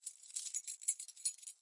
钥匙扣 " 钥匙扣10
描述：录音设备：Sony PCMM10Format：24 bit / 44.1 KHz
Tag: 样品 记录 弗利